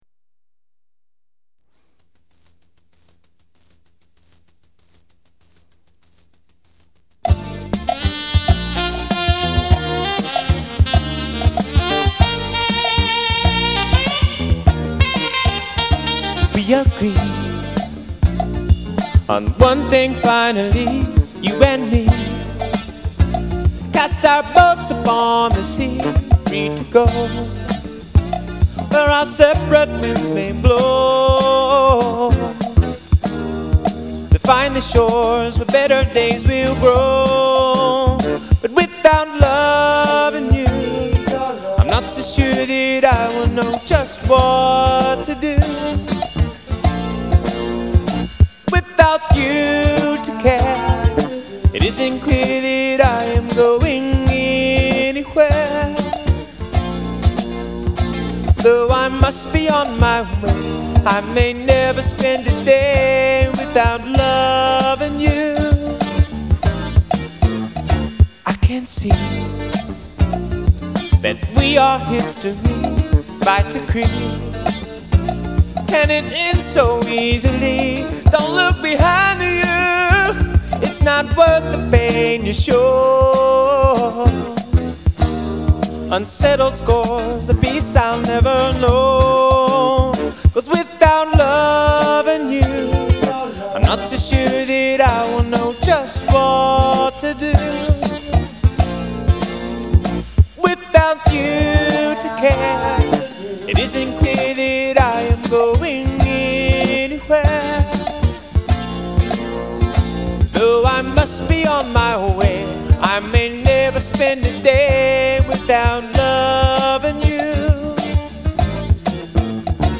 Without Loving You (original, old circa 1981 mp3 demo